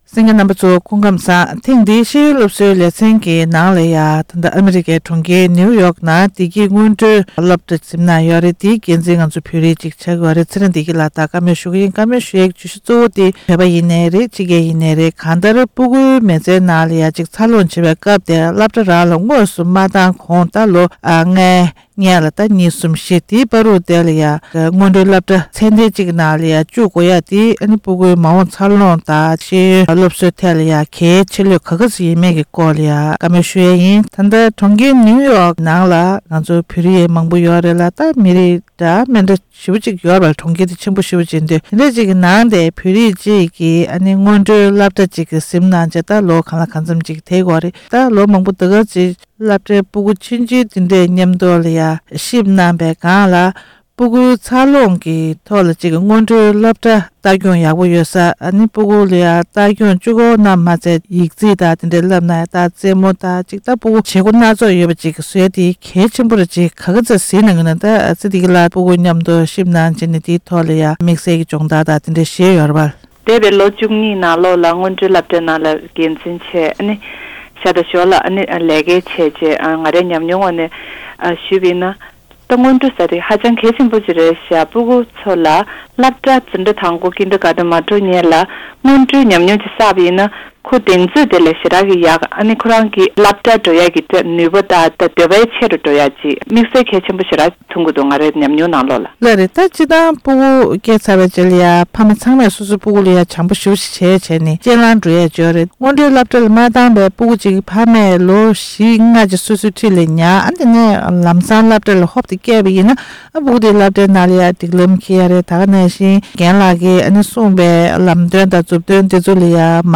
གནས་འདྲི་གནང་བ་ཞིག